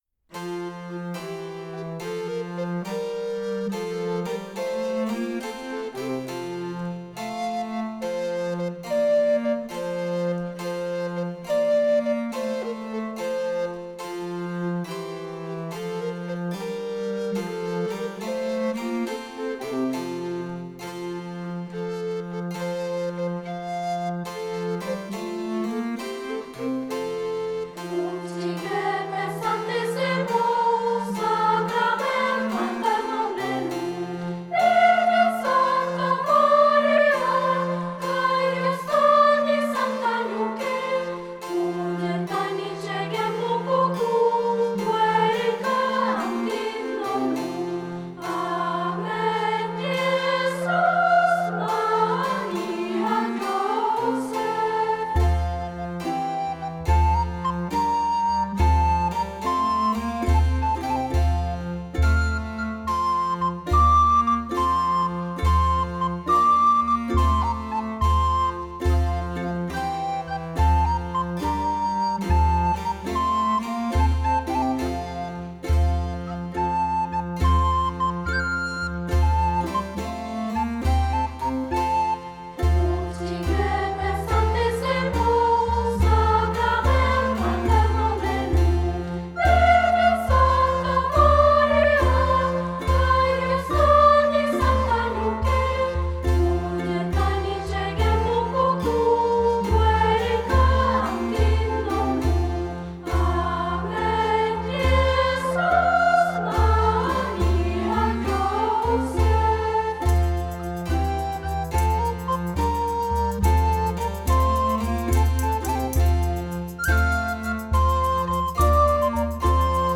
Música religiosa
Música vocal